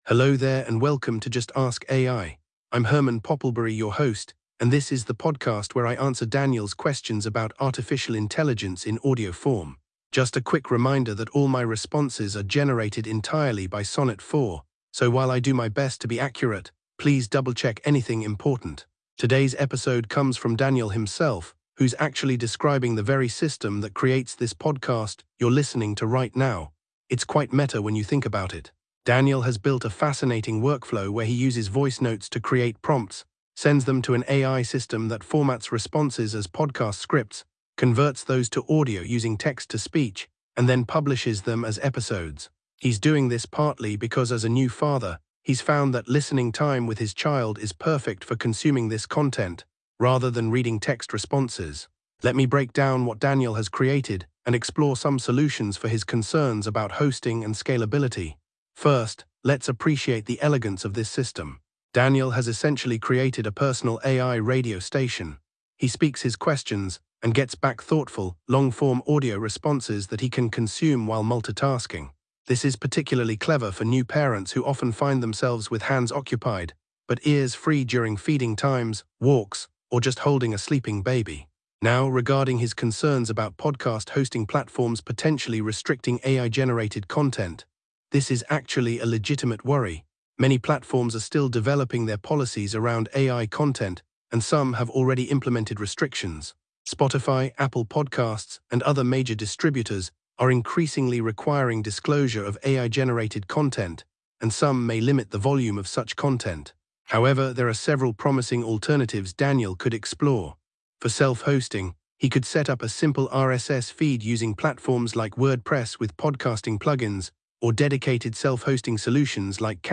AI-Generated Content: This podcast is created using AI personas.
This episode was generated with AI assistance. Hosts Herman and Corn are AI personalities.